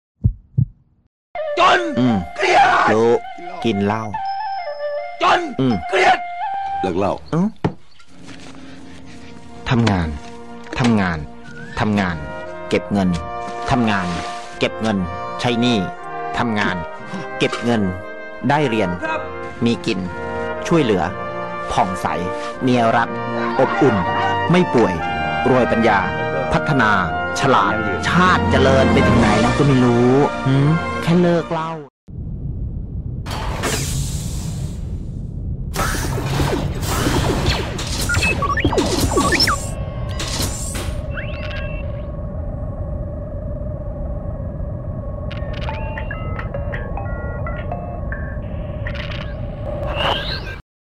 STOP DRINKING !! START GRINDING INSTEAD !! - THAI STYLE ADVERTISEMENT.. (THAILAND) >> MUST SEE FUNNY !!